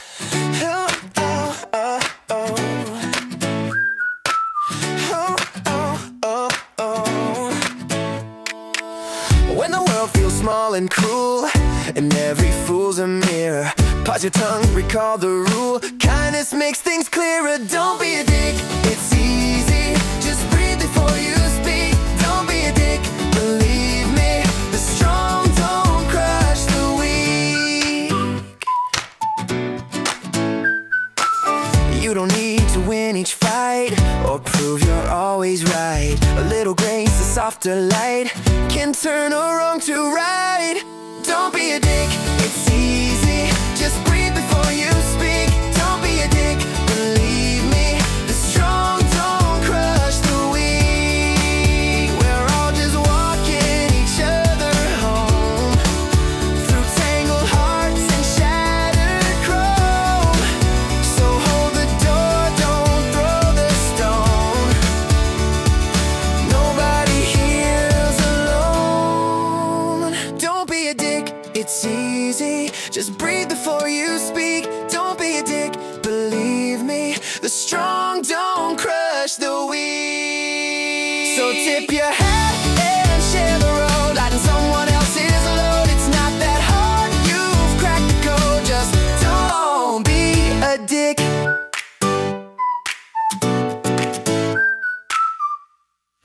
Rock